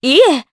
Juno-Vox-Deny_jp.wav